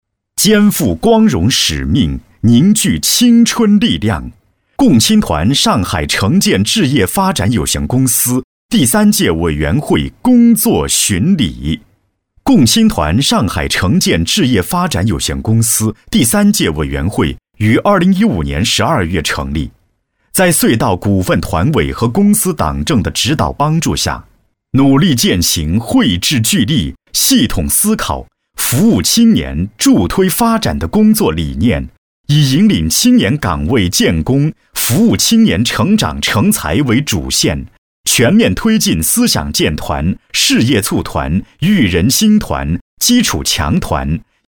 党建男36号（上海城建）
年轻时尚 企业专题,人物专题,医疗专题,学校专题,产品解说,警示教育,规划总结配音
大气浑厚，沉稳厚重，年轻时尚男中音。